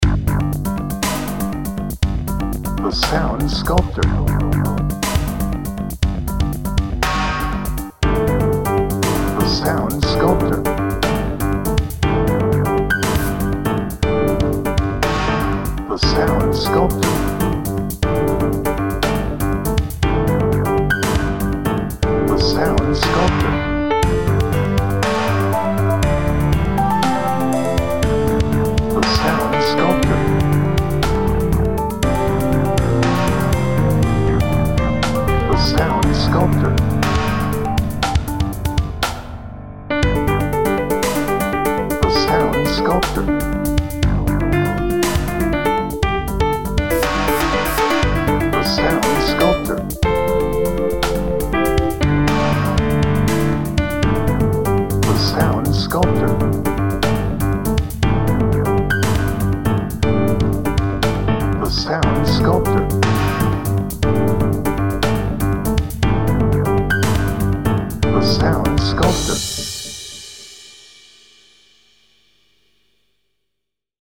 Edgy
Tense